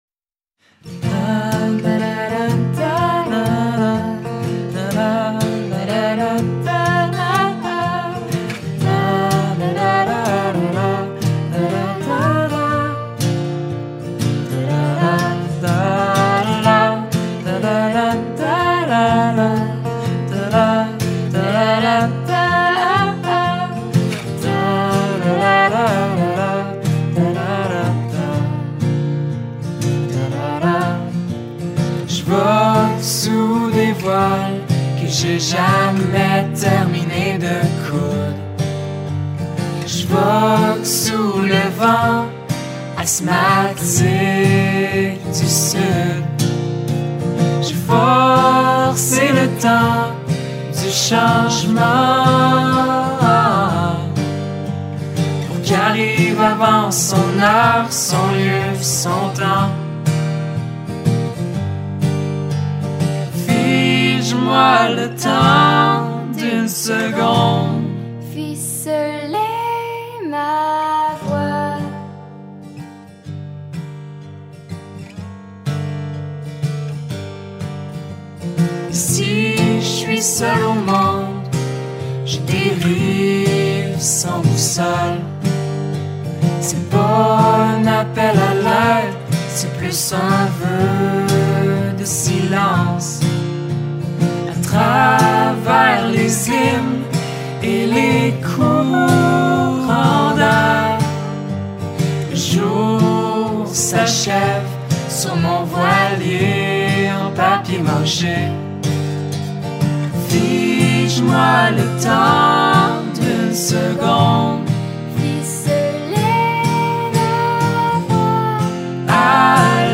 three-piece